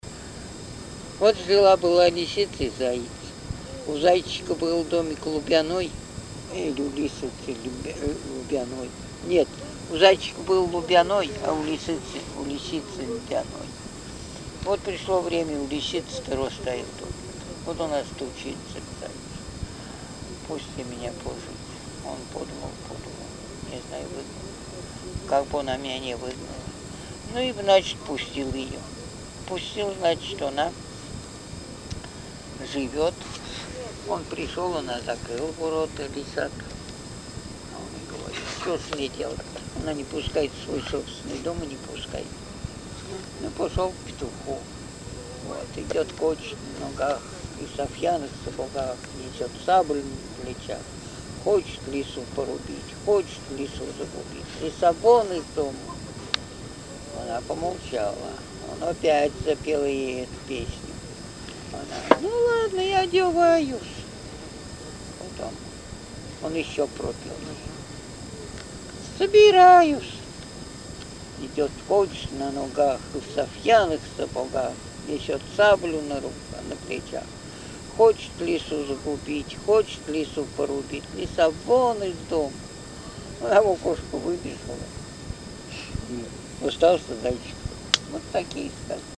Сказка